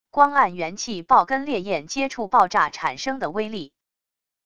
光暗元气爆跟烈焰接触爆炸产生的威力wav音频